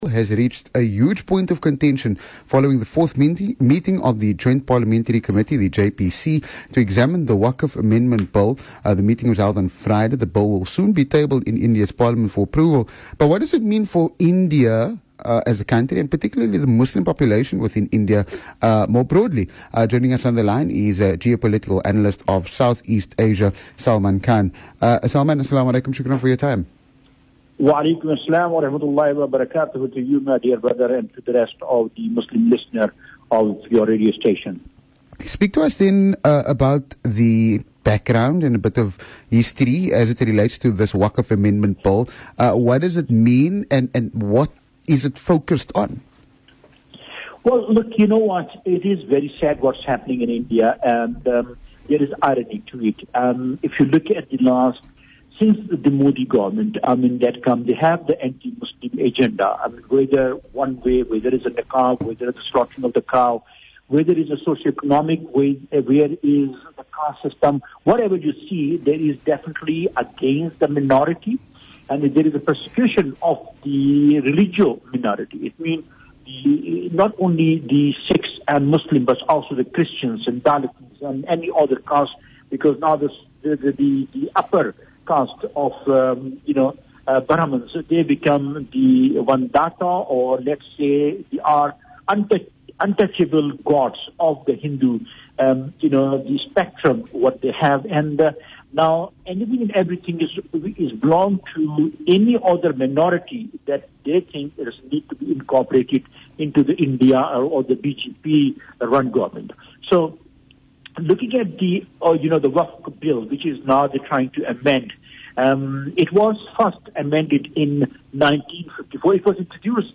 Listen further as he delves into conversation around this matter.